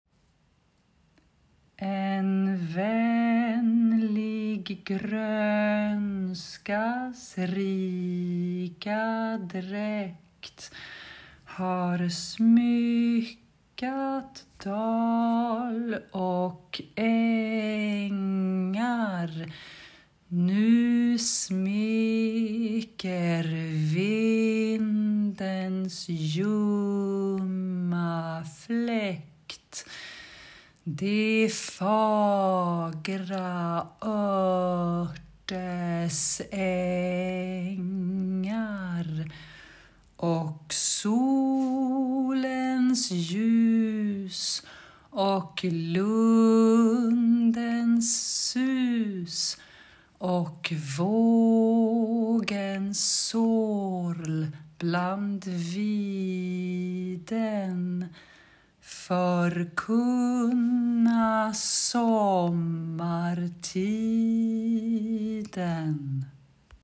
Summer Psalm – pronunciation guides:
Sommarpsalm_Pronunciation_Audio_TEST.m4a